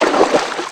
STEPS Water, Stride 05.wav